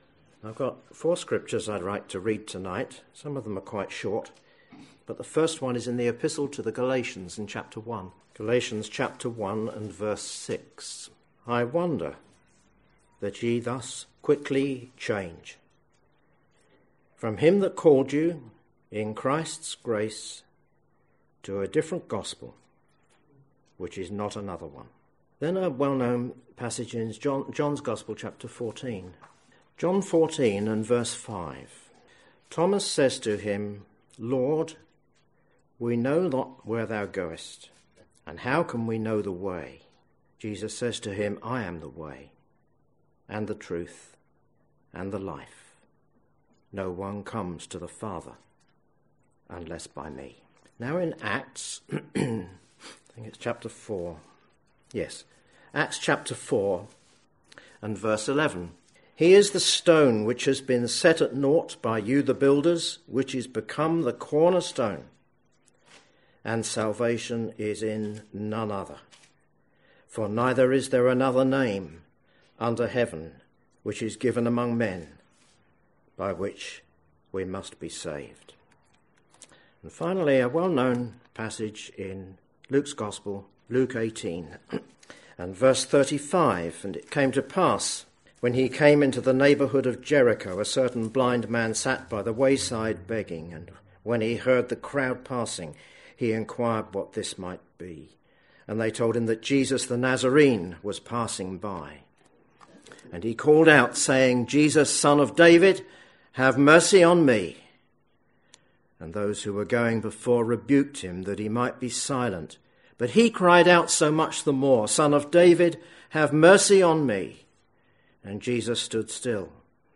Listen to this gospel message to discover how you can be saved and forgiven because of Jesus and his love.